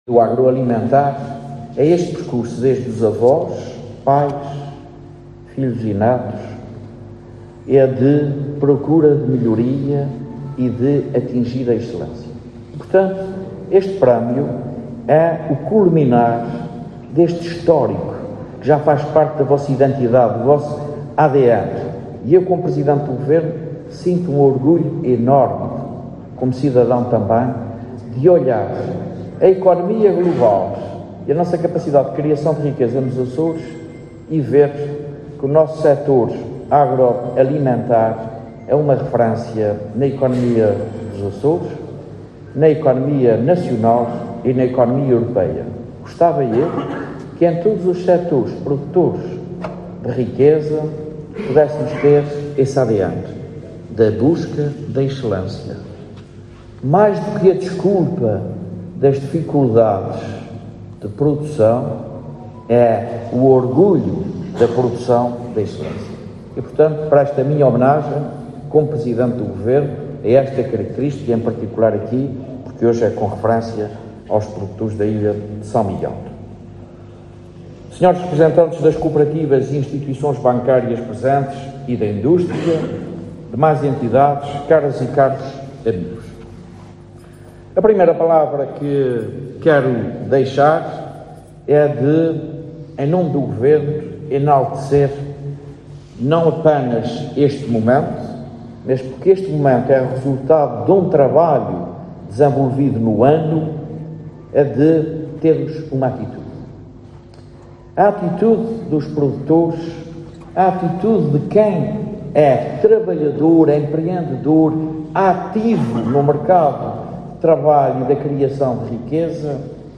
José Manuel Bolieiro falava em Ponta Delgada, na sessão de abertura dos prémios entregues a dezenas de agricultores pela Associação de Jovens Agricultores Micaelenses.